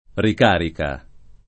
[ rik # rika ]